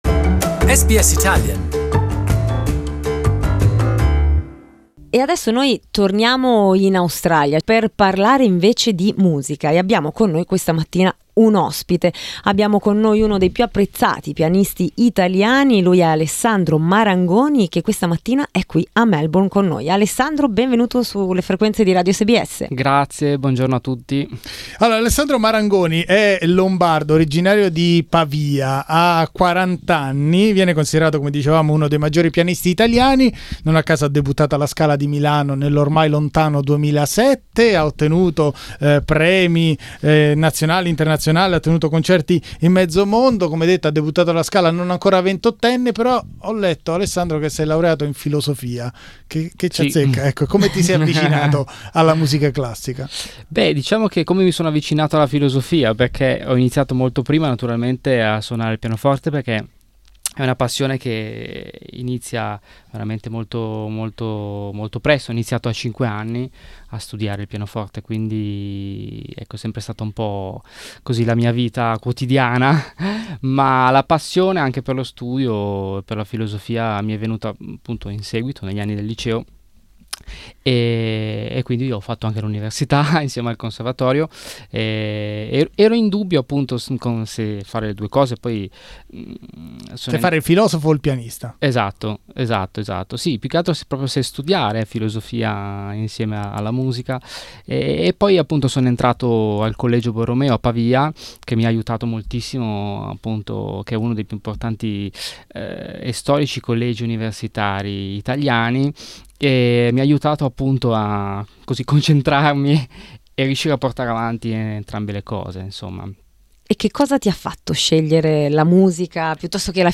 He came to visit us in our studios.